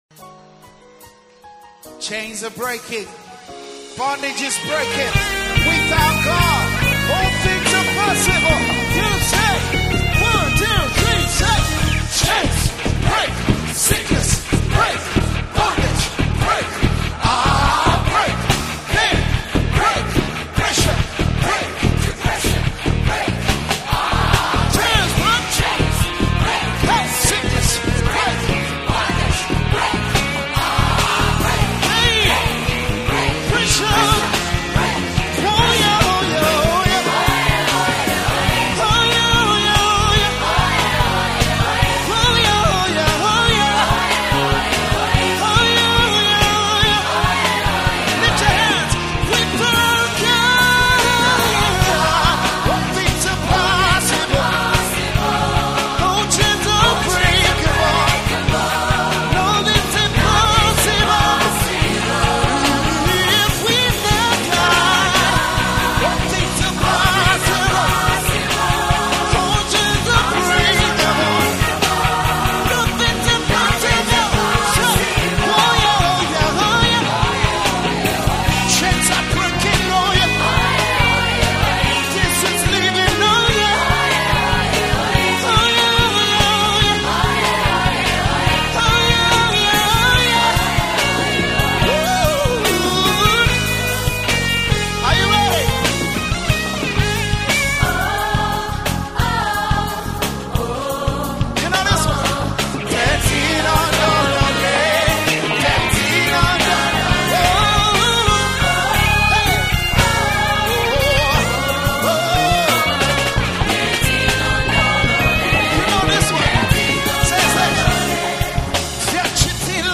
🎼 GENRE: ZAMBIAN GOSPEL / PRAISE